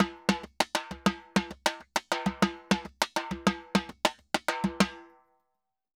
Timba_Salsa 100_1.wav